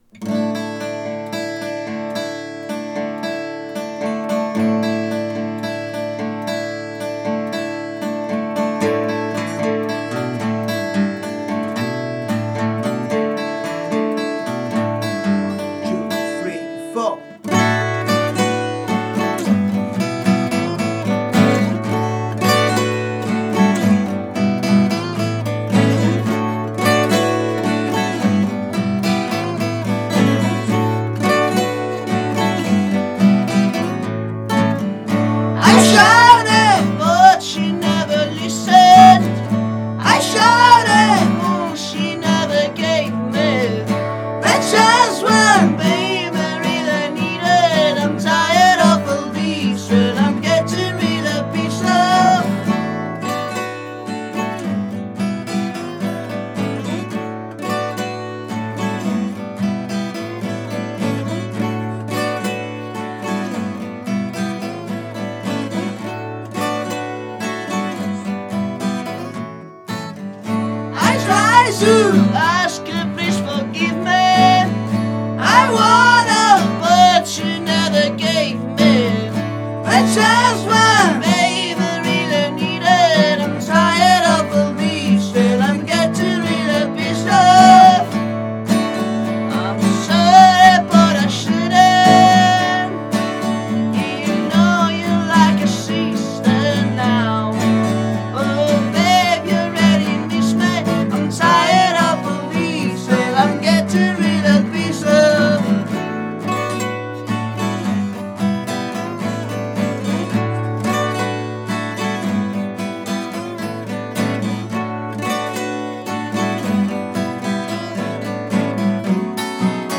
canzoni live unplugged